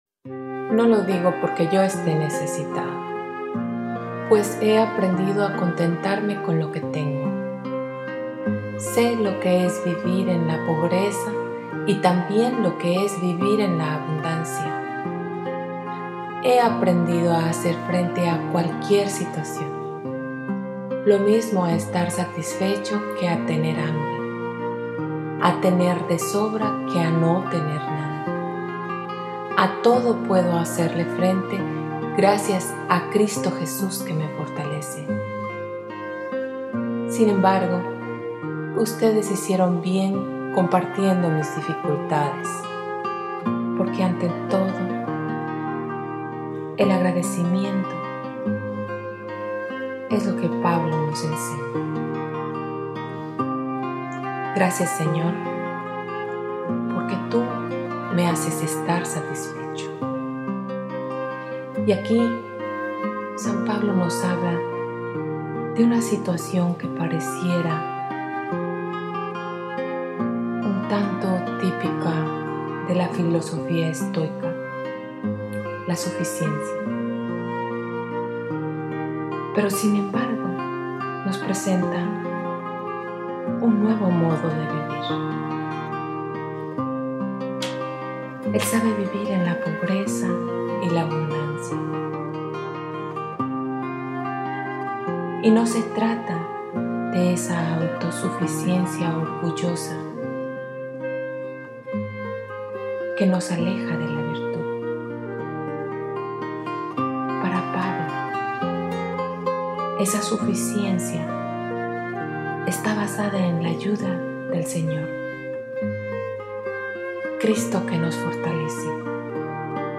LECTURA Y MEDITACION